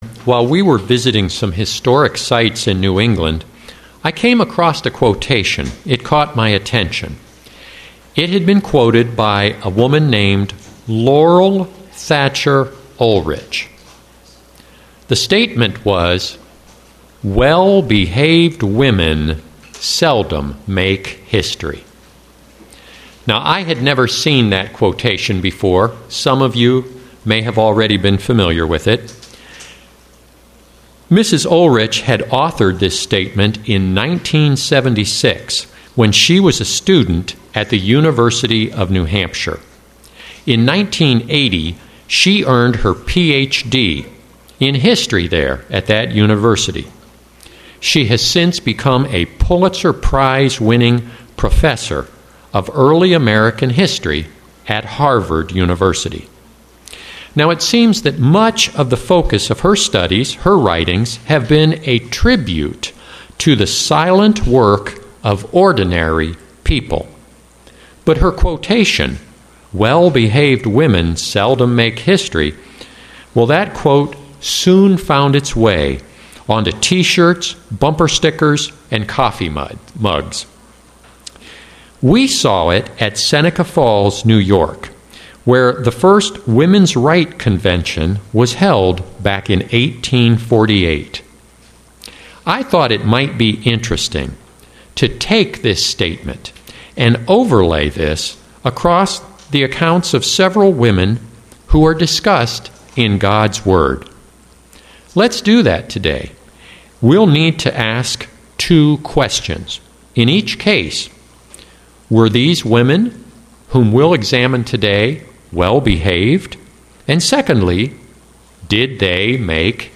Print Are Well Behaved Women Remembered UCG Sermon Studying the bible?